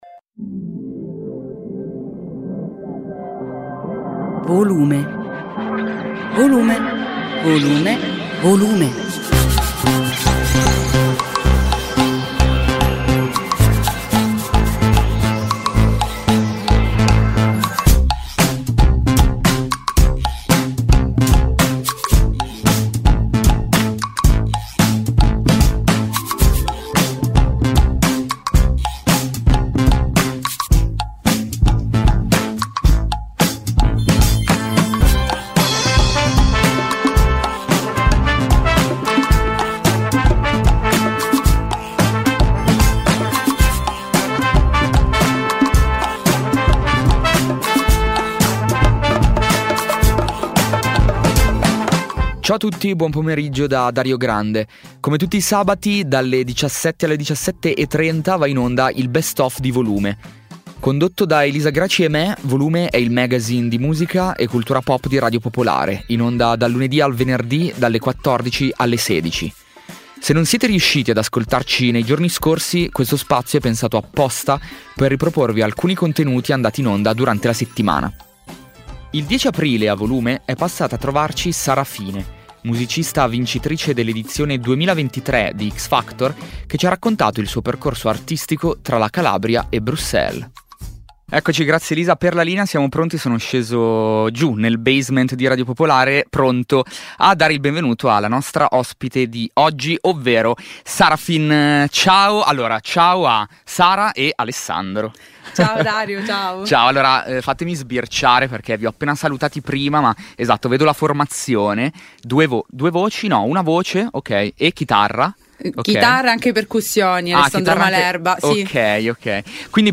I reportage e le inchieste di Radio Popolare Il lavoro degli inviati, corrispondenti e redattori di Radio Popolare e Popolare Network sulla...